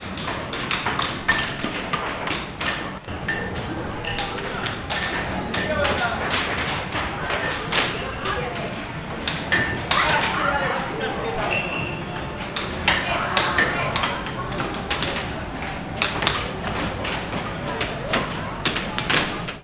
에어 하키 특유의 소리